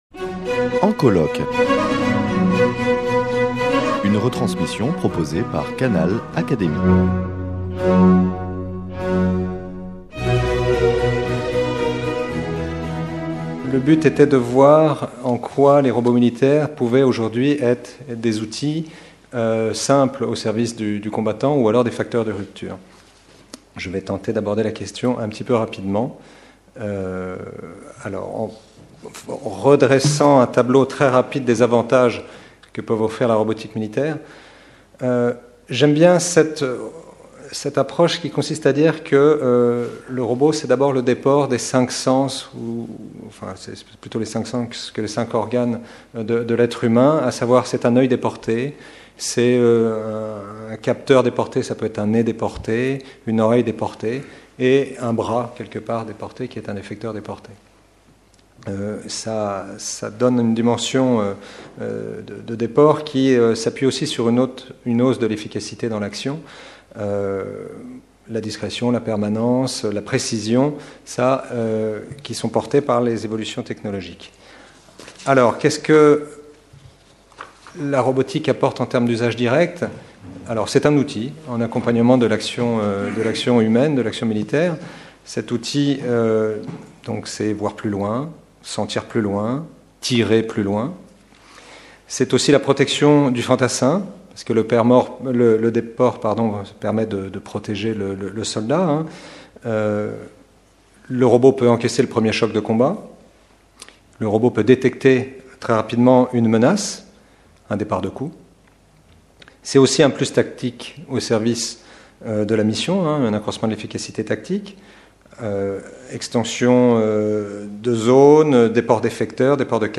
prononcée le 15 octobre 2016 lors des journées d’étude « Guerre et technique » organisées dans le cadre du programme de recherche « Guerre et société » soutenu par la Fondation Simone et Cino del Duca et l’Académie des sciences morales et politiques.